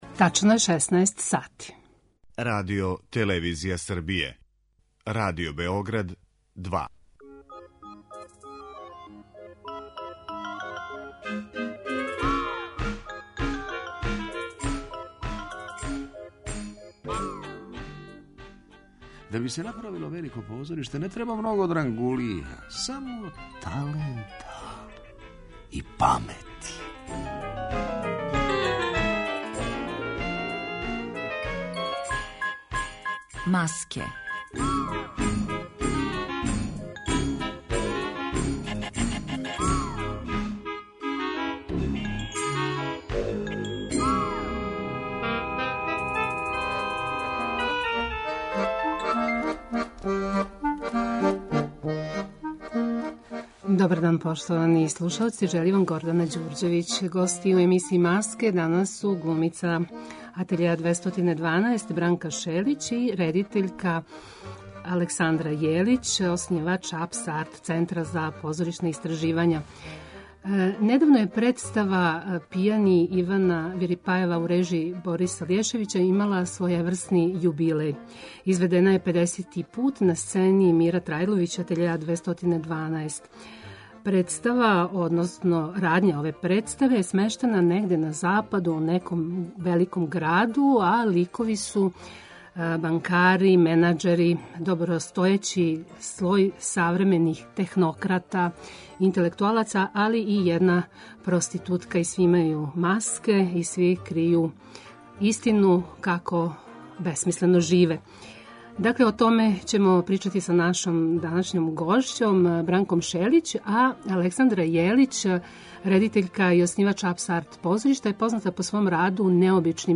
Емисија посвећена позоришту